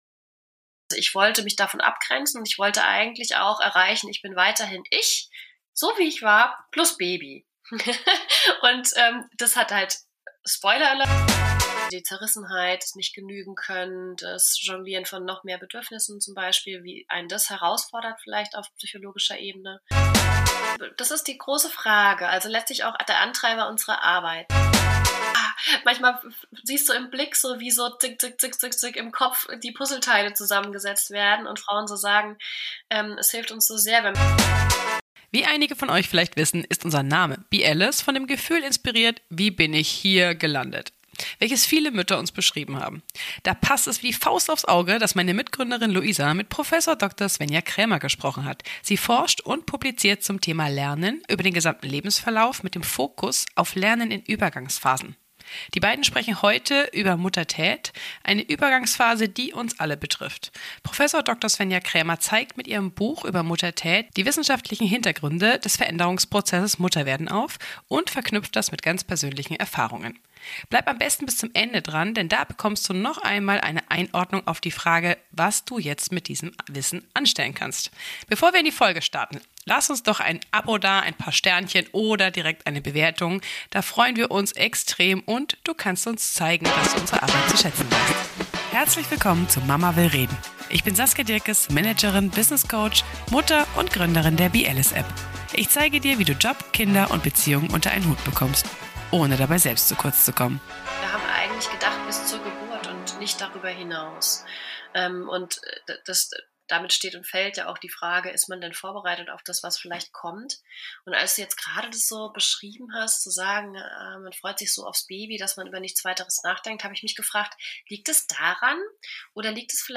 Darum erwarten dich sowohl spannende Interviews mit inspirierenden Frauen als auch handfeste Coaching-Tipps für mehr Gelassenheit und Klarheit im Alltag.